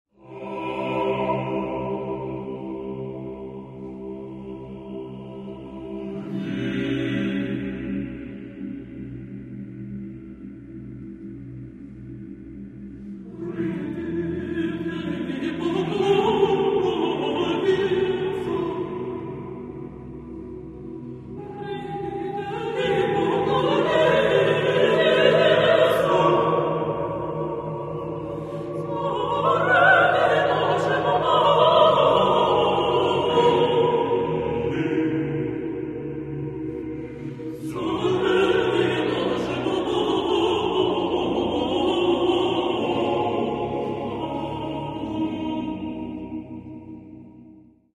Catalogue -> Classical -> Choral Art